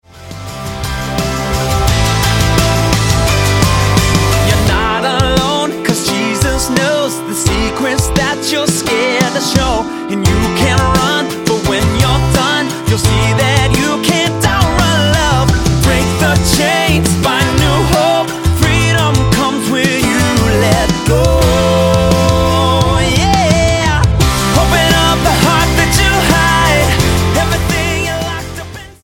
a little bit country, a little bit pop and roll
Style: Pop